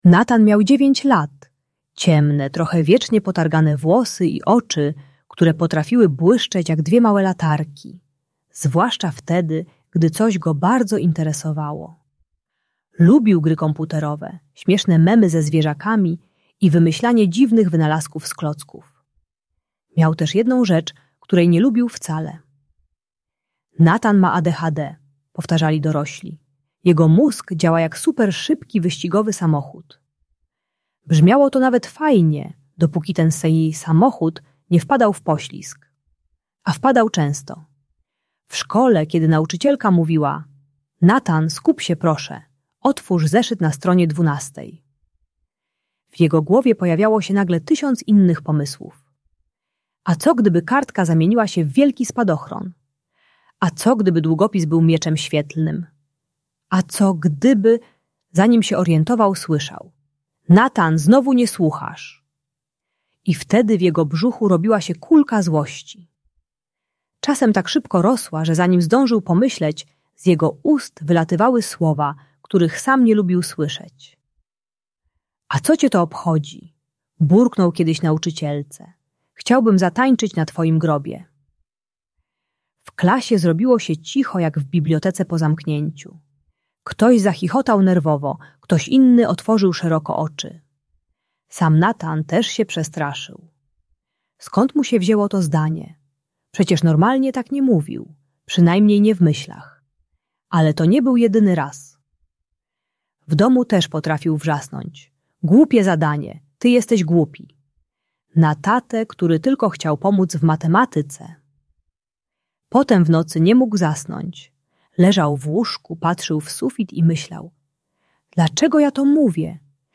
Historia uczy techniki "3 sekund" - zatrzymania się przed wypowiedzeniem złych słów. Audiobajka o kontrolowaniu impulsywności i agresji słownej u dziecka z ADHD, które krzyczy na rodziców i nauczycieli.